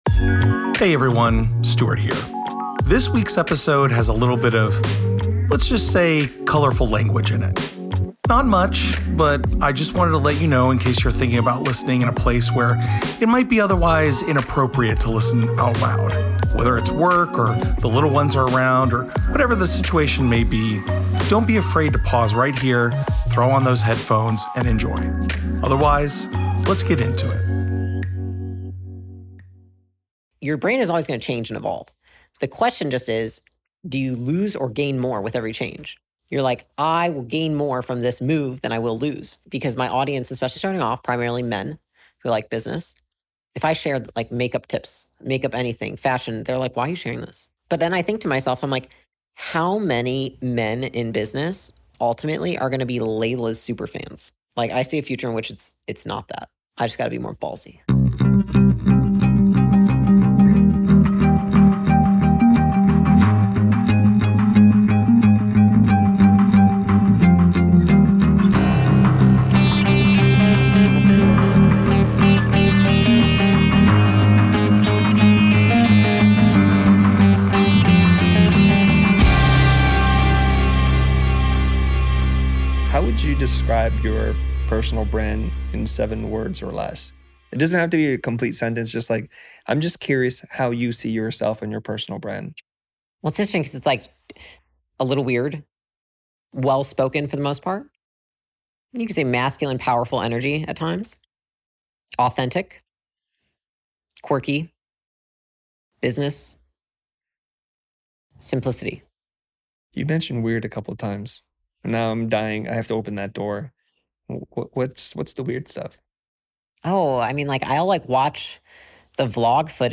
In the second part of a two-part interview, Leila and Chris focus on some of the lessons she has learned from running her own businesses and being an investor in others. They talk about what Leila wants to focus on when investing in new businesses, the ins and outs of embracing personal quirks as a business leader, why critique is more important than compliments, and the transformative power of self-acceptance.